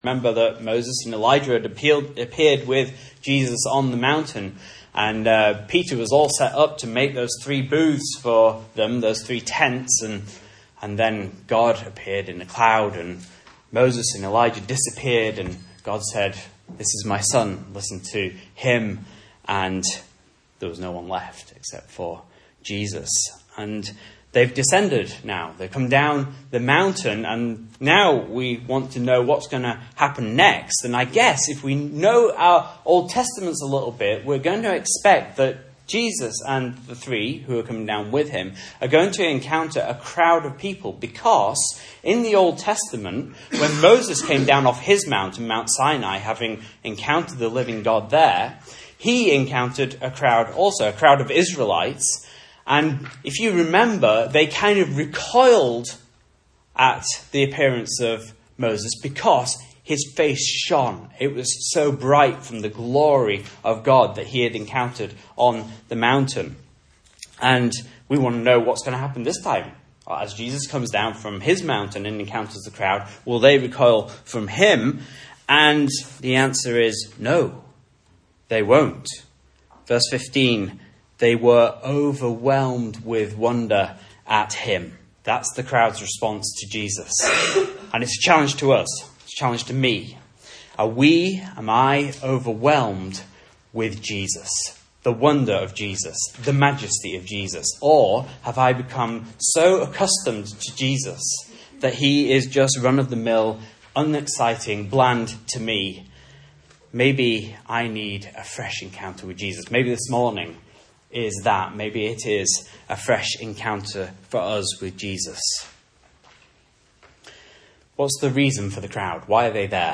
Message Scripture: Mark 9:14-32 | Listen